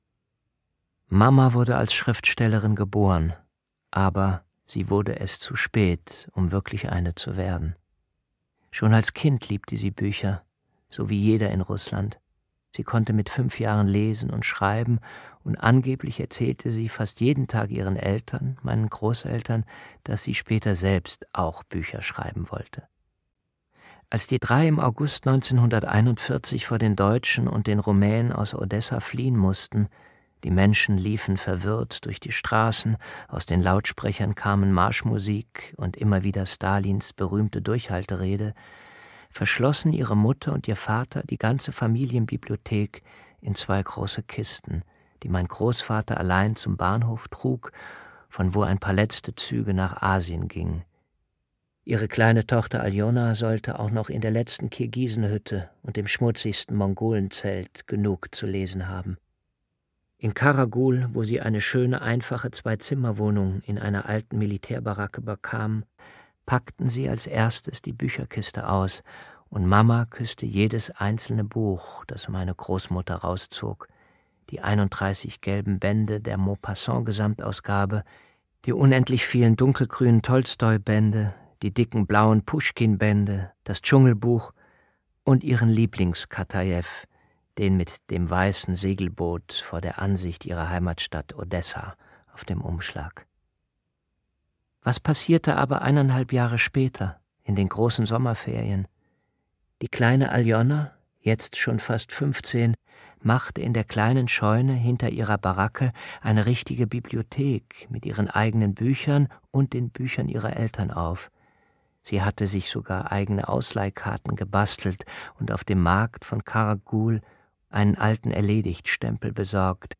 Mama Odessa Roman Maxim Biller (Autor) Jens Harzer (Sprecher) Audio Disc 2023 | 2.